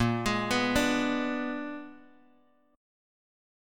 Bb7b9 Chord
Listen to Bb7b9 strummed